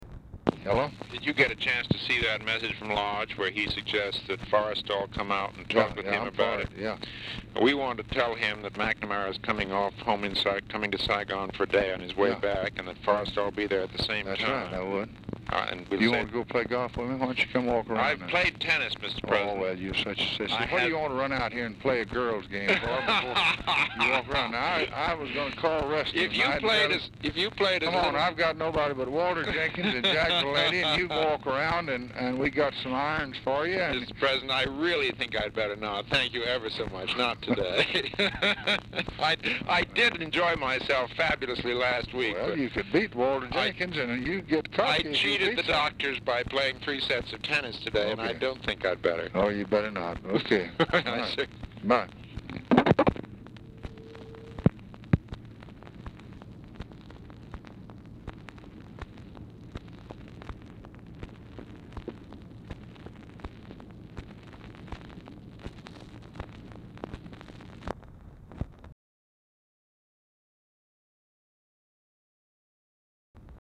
Telephone conversation # 3324, sound recording, LBJ and MCGEORGE BUNDY, 5/2/1964, 4:31PM | Discover LBJ
Format Dictation belt
Location Of Speaker 1 Oval Office or unknown location
Specific Item Type Telephone conversation